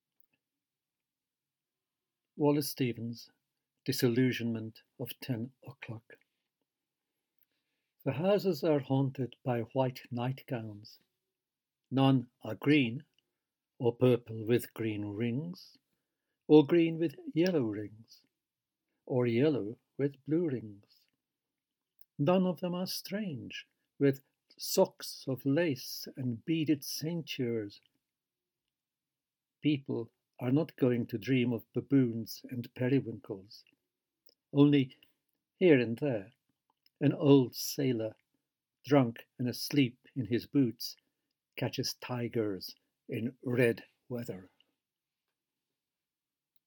George Szirtes reads disillusionment of 10 O Clock by Wallace Stevens
George-Szirtes-reads-disillusionment-of-10-O-Clock-by-Wallace-Stevens.mp3